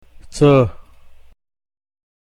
has no "sound equivalent" in English, but is best approximated by the sound of the letter Z in G.mp3an word  ZUCKER (Sugar), or by the letters ZZ in the English pronunciation of the Italian word PIZZA.